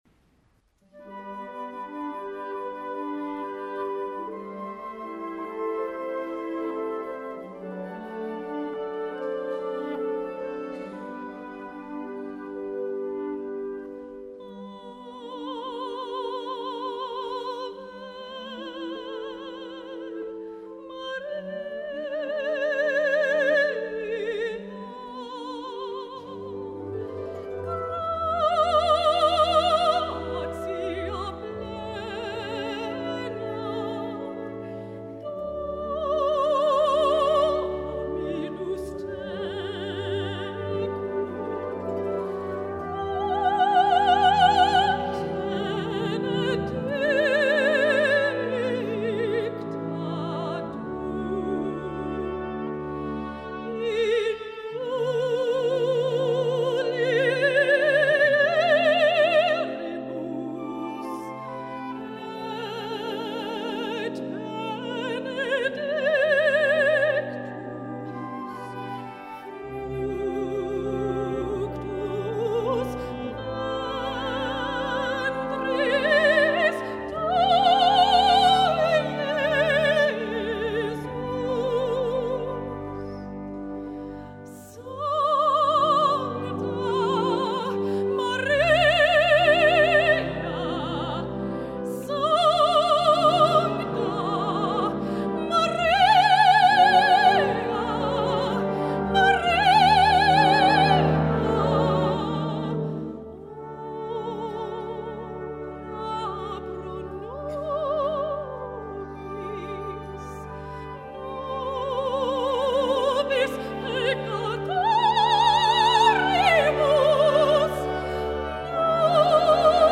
Voicing: Vocal Solo w/ Band